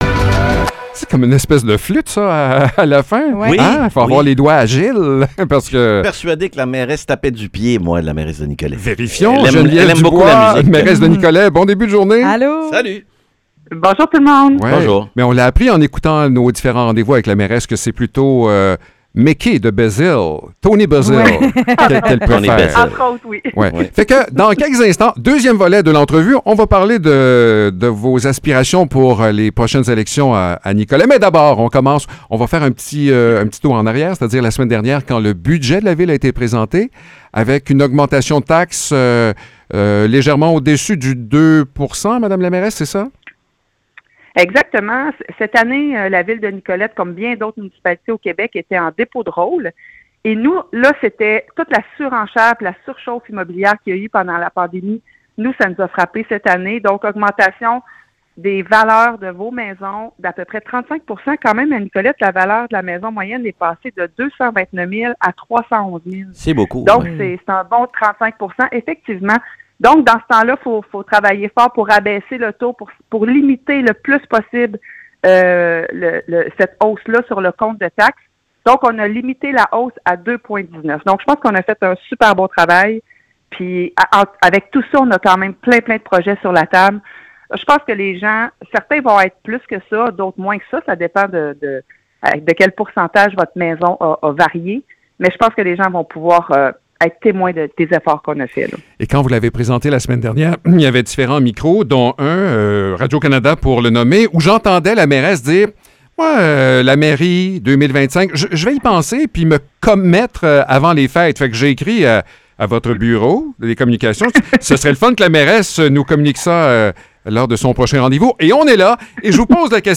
Échange avec la mairesse de Nicolet